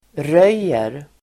Uttal: [r'öj:er]